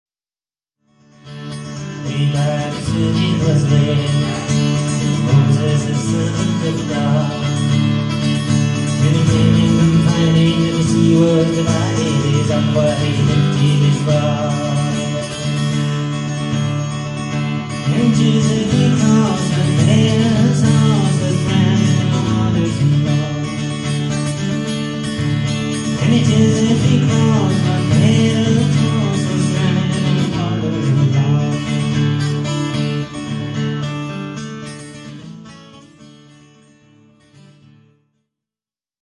Malga Ime (Verona - Italy)